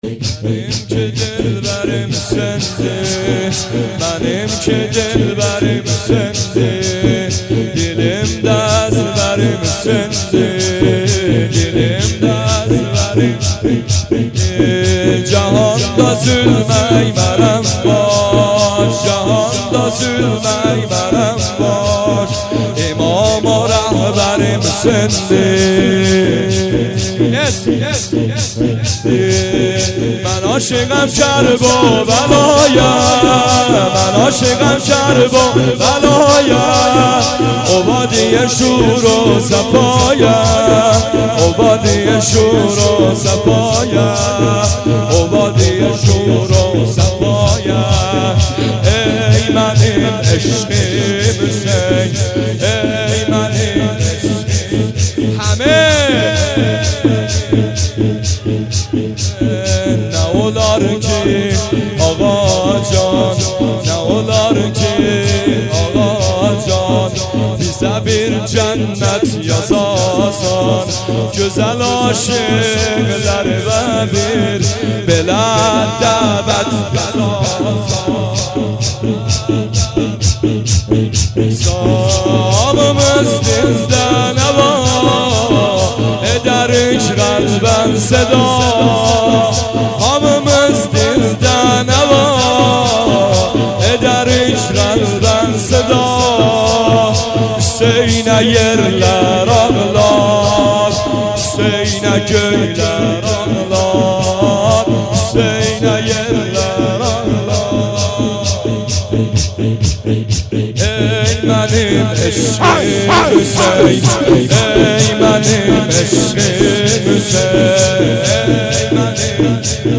سینه زنی شور | منیم که دلبریم
محرم93